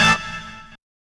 68_06_stabhit-A.wav